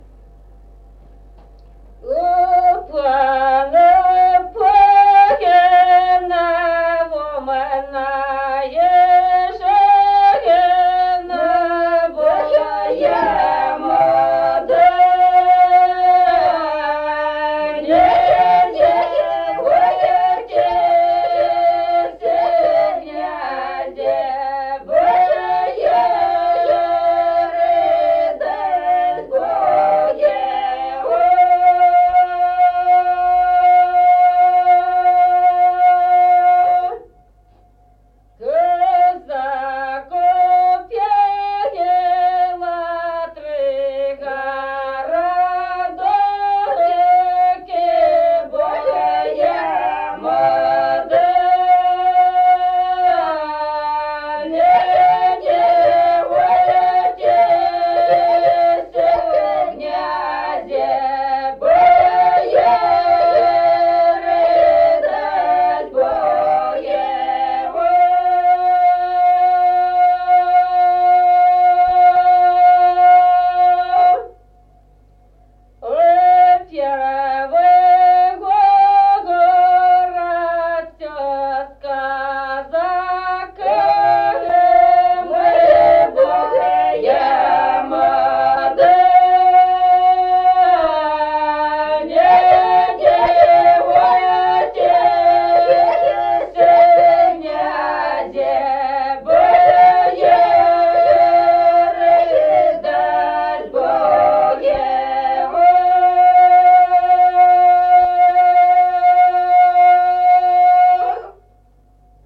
| filedescription = У пана, пана (щедровка).
Песни села Остроглядово.